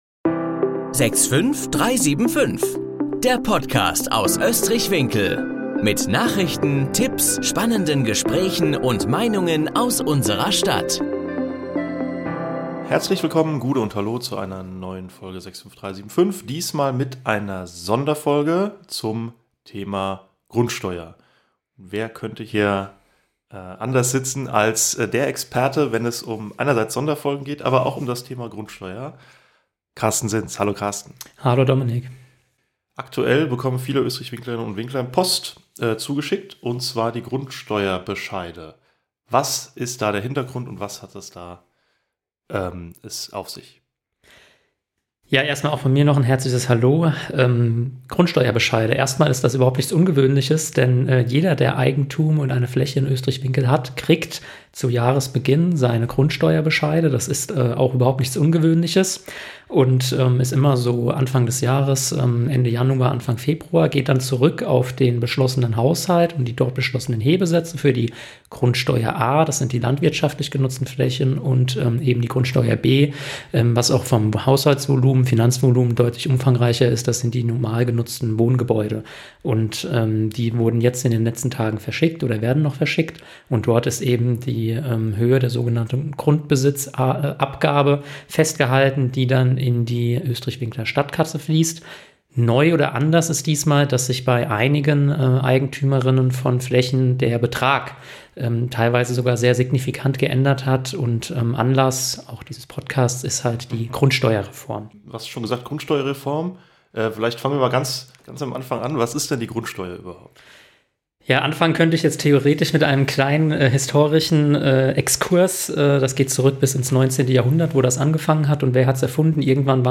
In dieser Folge sprechen wir mit dem Bürgermeister Carsten Sinß über die wichtigsten Änderungen, erklären, was Eigentümer/innen beachten müssen, und geben Tipps zum Umgang mit den neuen Anforderungen.